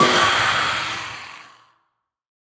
Minecraft Version Minecraft Version 25w18a Latest Release | Latest Snapshot 25w18a / assets / minecraft / sounds / mob / phantom / death1.ogg Compare With Compare With Latest Release | Latest Snapshot
death1.ogg